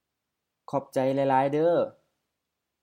Isaan Pronunciation Tones
ขอบใจ khɔ:p-jai LF-M